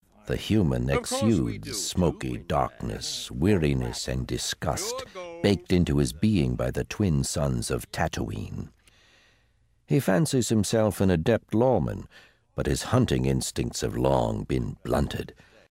In the audio dramatization of Nightlily: The Lovers' Tale